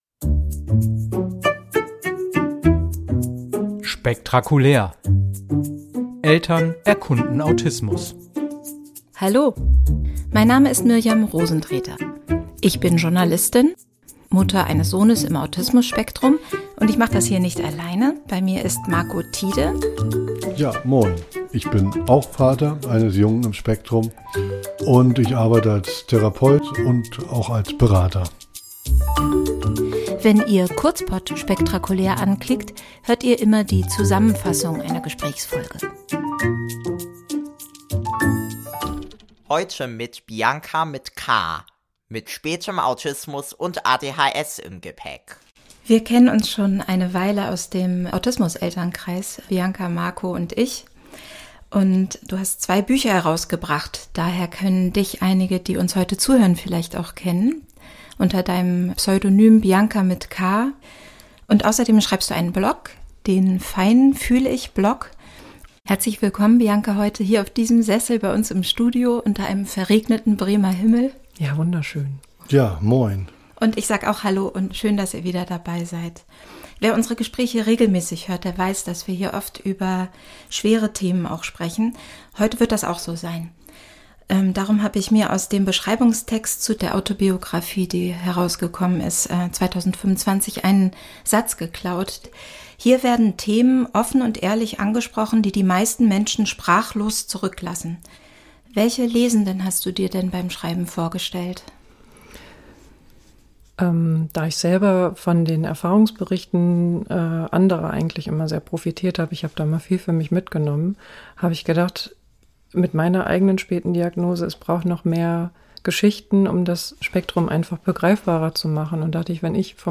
Es ist ein Gespräch über Trauer und den Umgang mit Ausnahmesituationen. Und doch steckt es voller Zuversicht und sogar Humor.